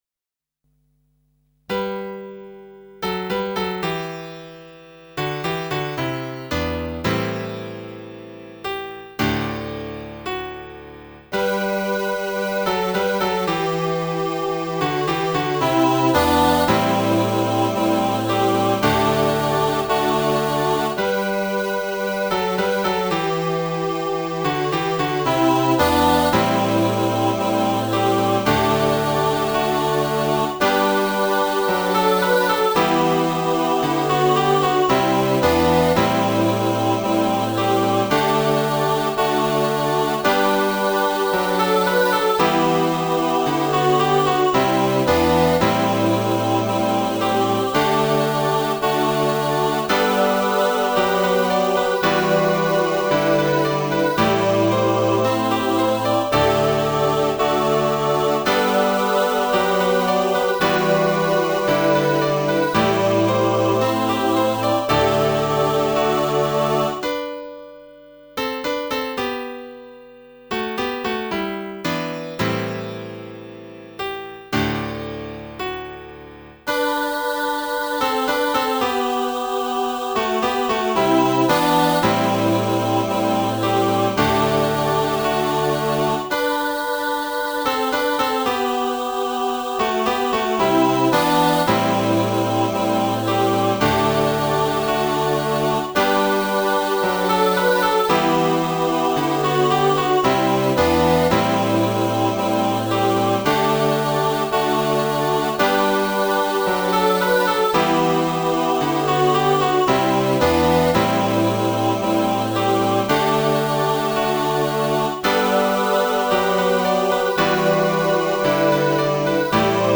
Lord, your word is truth (Ps 119 revisited) Choir (Words)